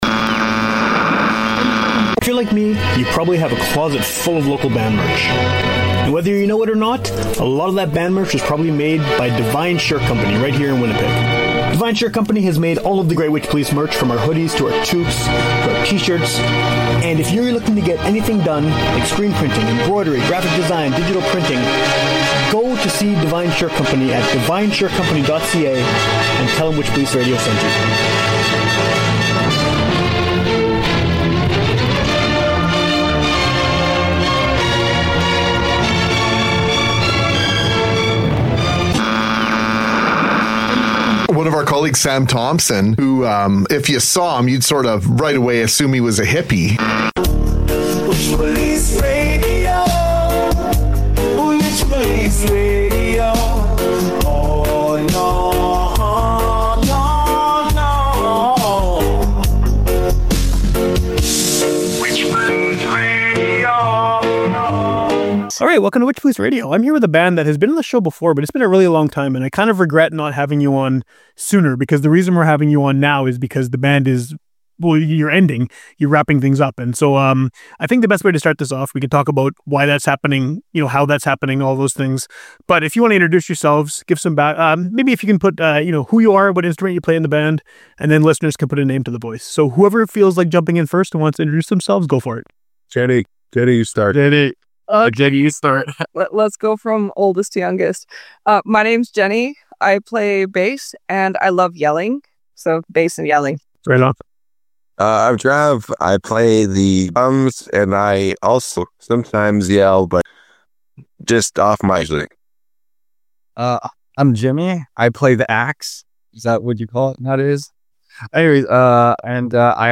Note: one of the band members had kind of a shitty connection, so it can be a bit choppy when he speaks, but it doesn't detract from the overall quality of the conversation.